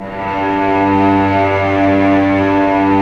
Index of /90_sSampleCDs/Roland LCDP13 String Sections/STR_Vcs FX/STR_Vcs Sul Pont